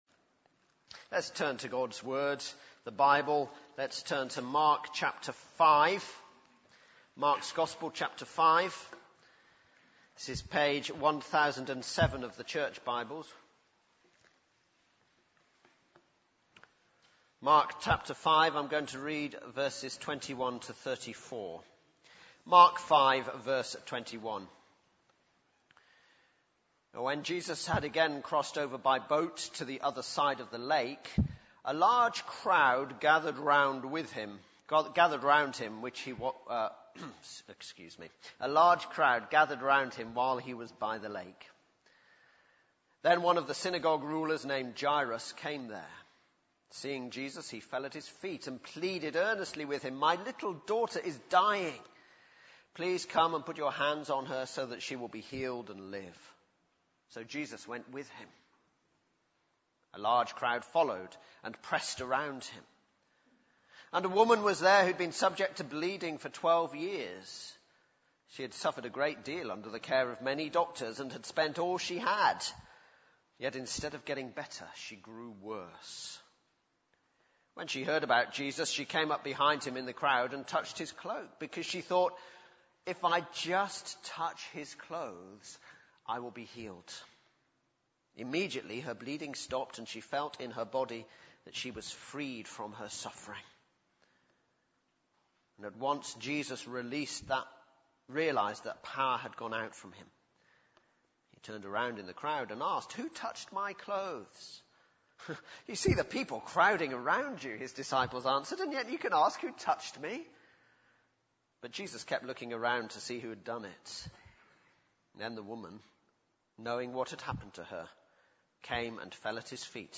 Baptismal Service – July 2013